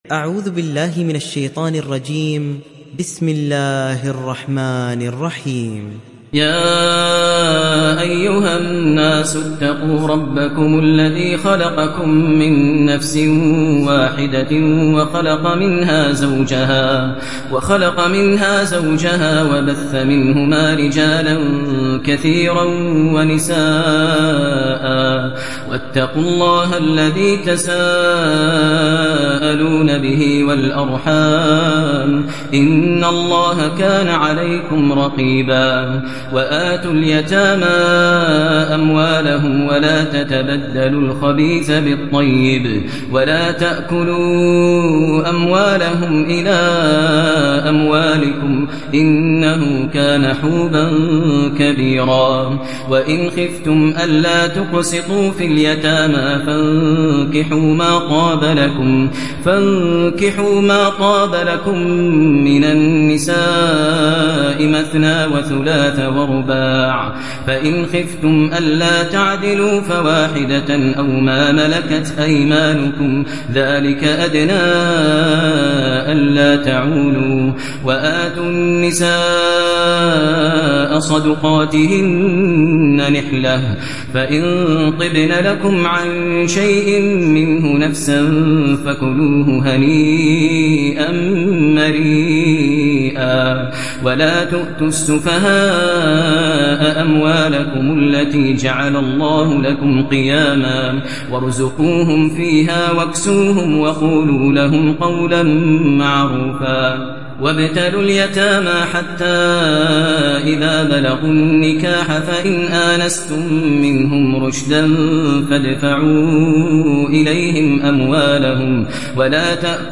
Nisa Suresi İndir mp3 Maher Al Muaiqly Riwayat Hafs an Asim, Kurani indirin ve mp3 tam doğrudan bağlantılar dinle